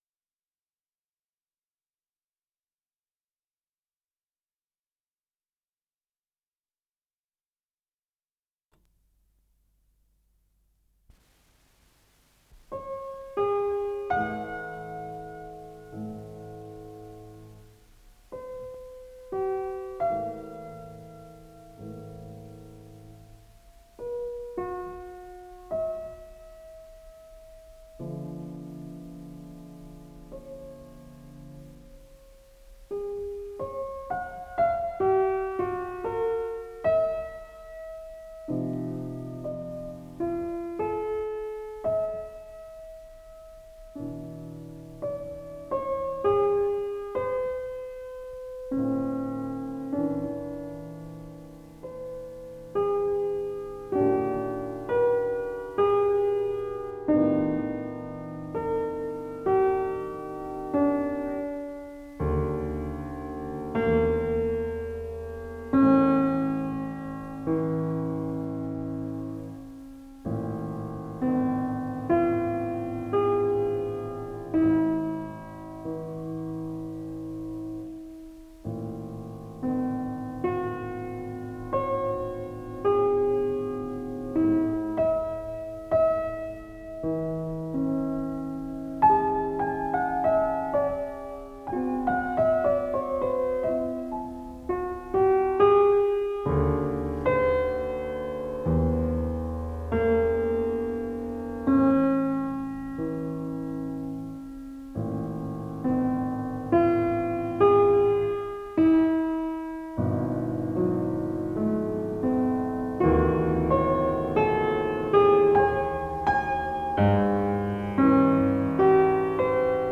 фортепиано
ВариантДубль моно